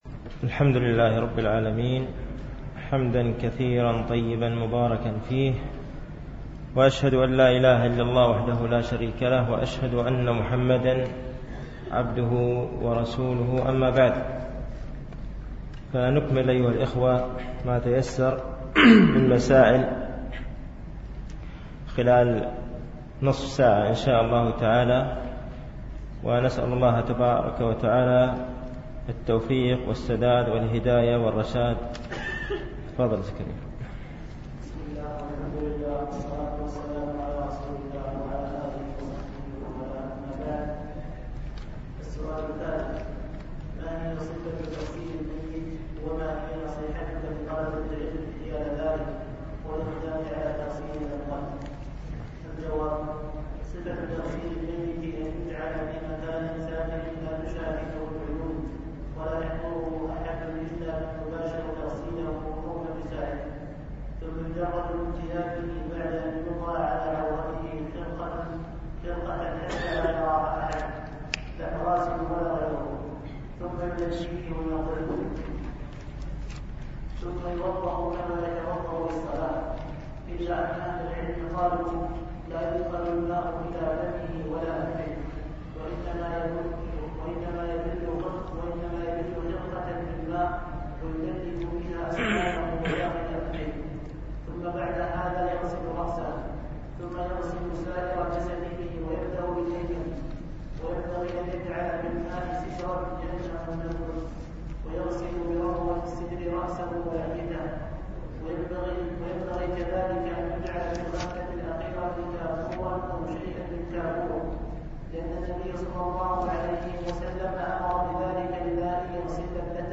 التعليق على رسالة 70 سؤالا في أحكام الجنائز ـ الدرس الثاني